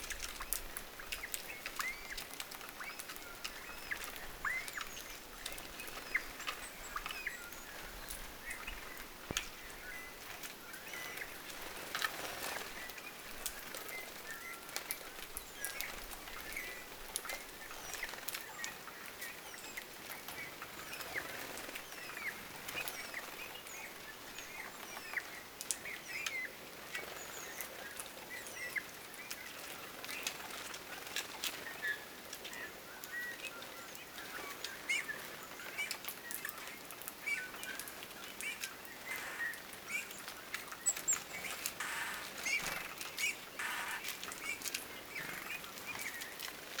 pähkinähakin hiljaista ääntelyä
pahkinahkin_hiljaista_aantelya.mp3